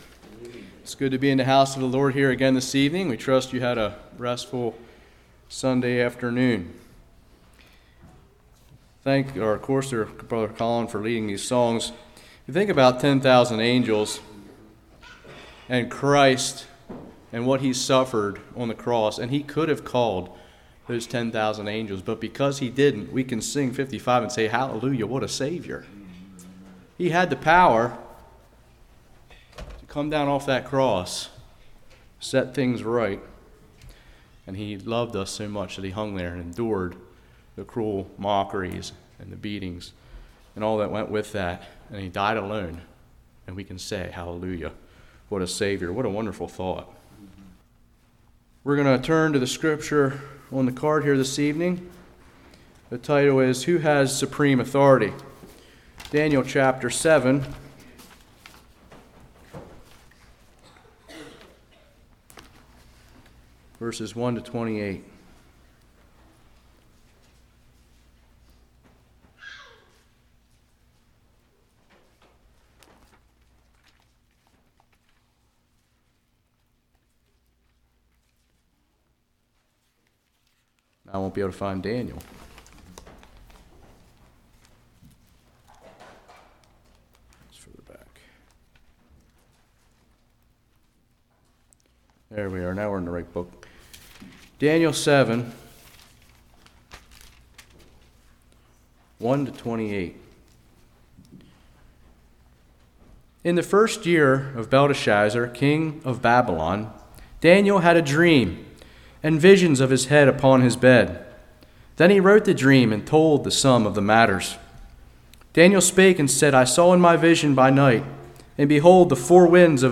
Daniel 7:1-28 Service Type: Revival Prophecy-Its Purpose Do not scoff at prophecy.